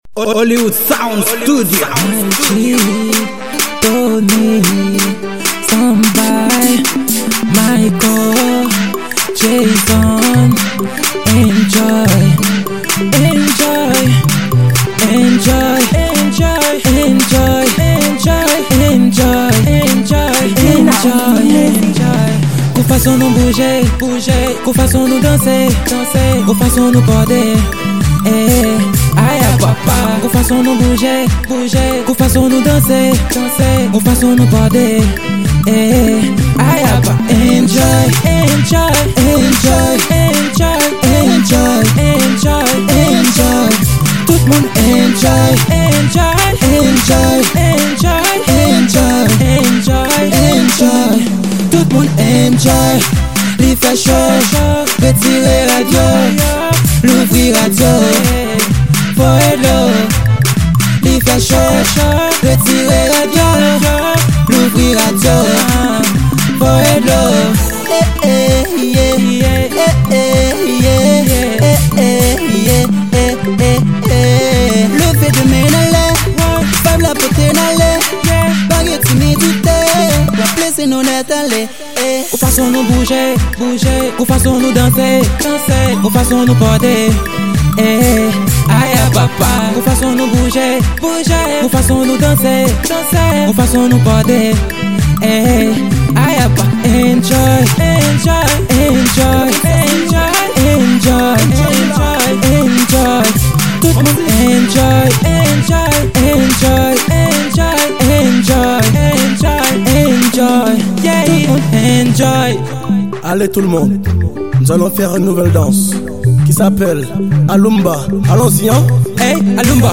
Genre: WORLD.